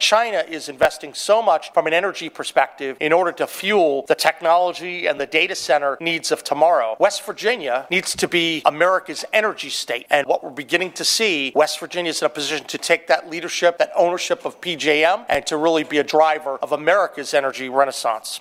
Governor Patrick Morrisey held a “West Virginia’s Comeback” press conference, dedicated to giving a review of accomplishments in his first year in office.  His main focus of his first year has been economic development and making West Virginia America’s energy source.